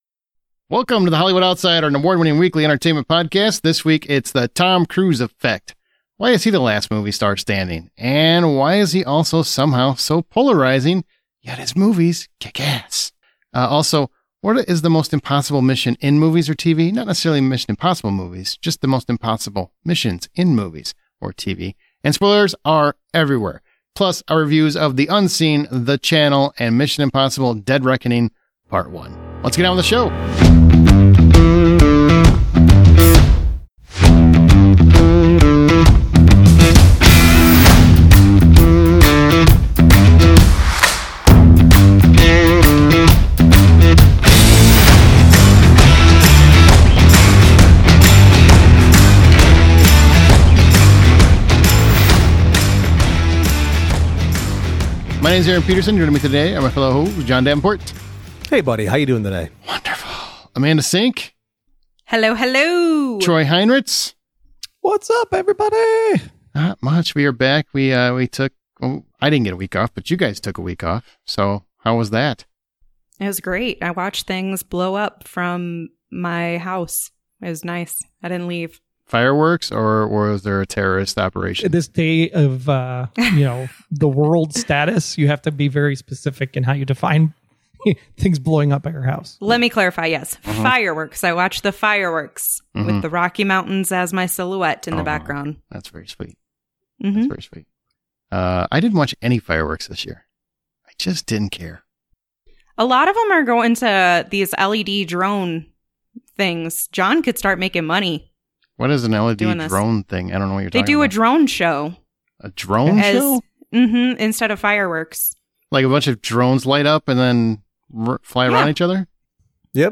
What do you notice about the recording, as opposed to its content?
Reviews of Mission: Impossible - Dead Reckoning Part One, The Channel, and The Unseen. Plus interviews from the premiere of The Unseen.